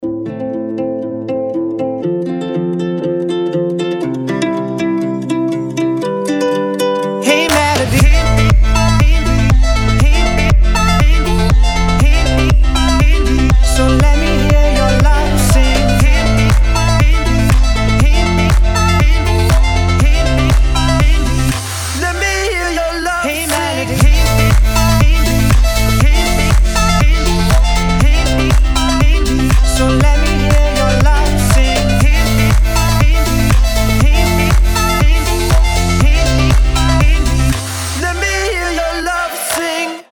гитара
красивые
deep house
мелодичные
house